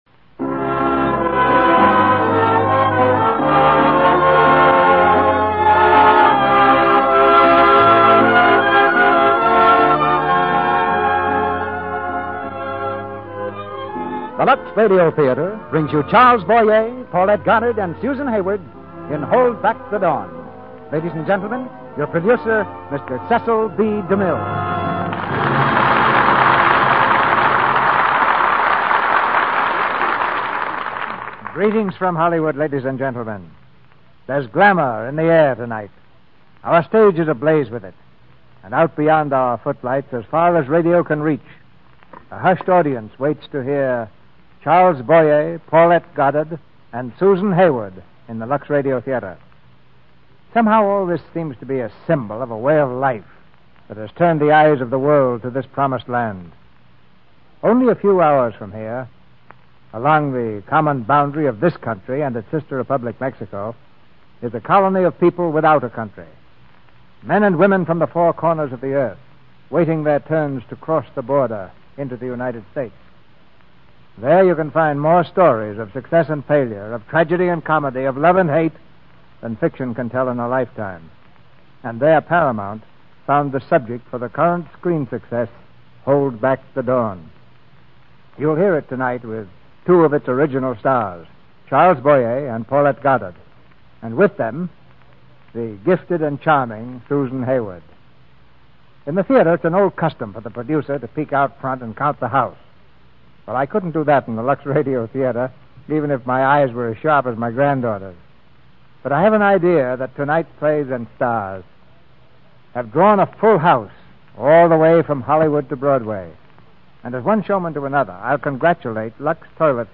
Lux Radio Theater Radio Show
Hold Back the Dawn, starring Charles Boyer, Paulette Goddard, Susan Hayward